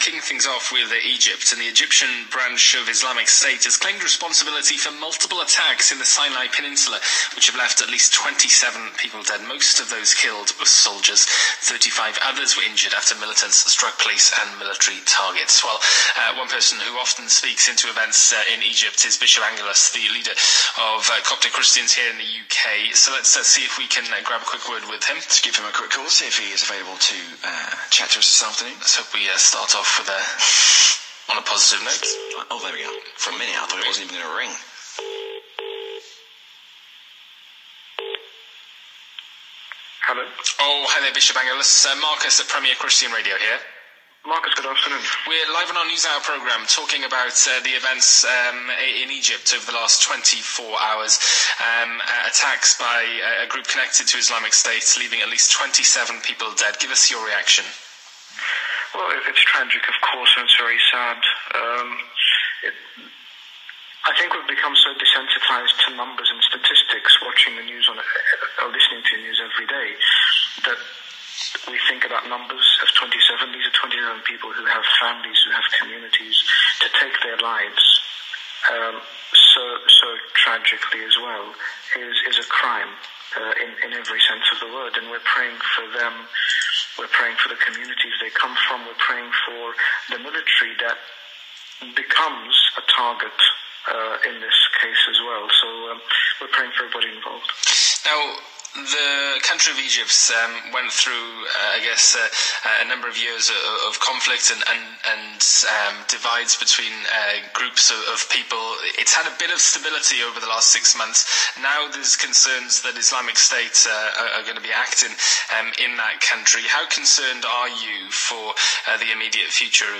Premier Radio Interview re Egypt with HG Bishop Angaelos
Premier Egypt Interview.mp3